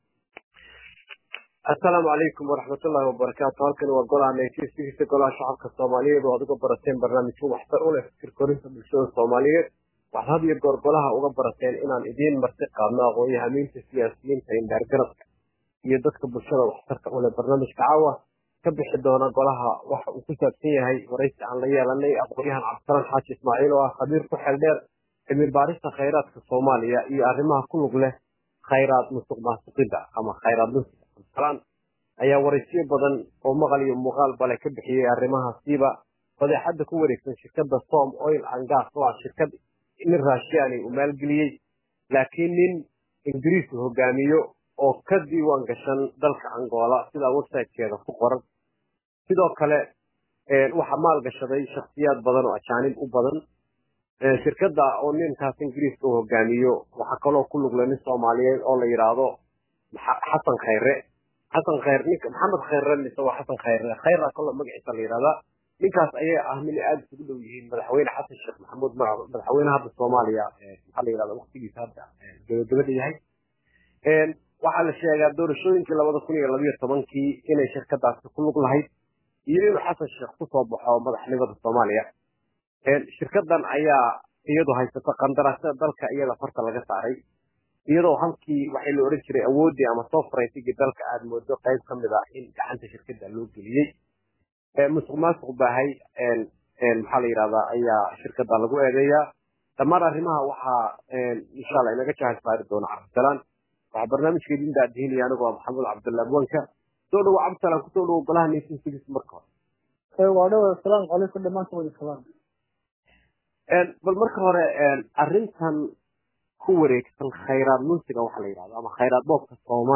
Waraysigani wuxuu xambaarsan yahay cilmi baaris xaqiiqa ku salaysan iyo warbixin dheer oo ku aadan BOOBKA KHAYRAADKA BADDA SOMALIYA IYO SOMA OIL.